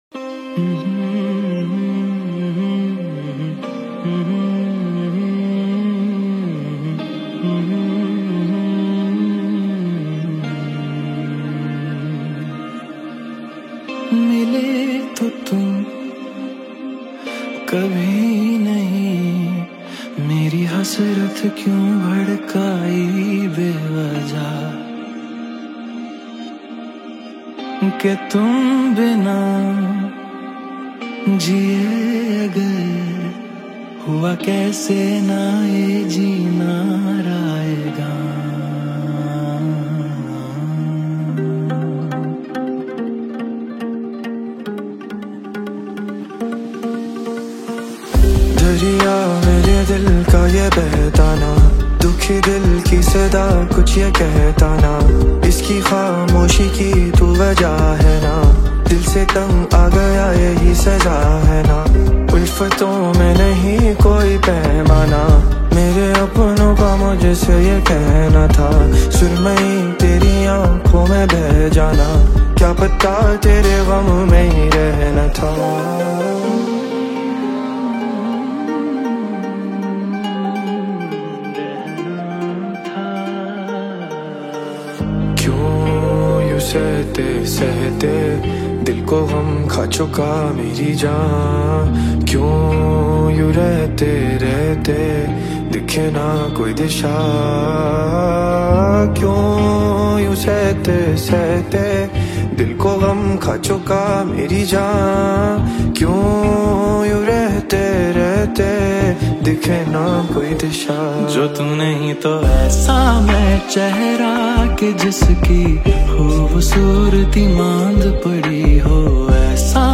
modern yet soulful touch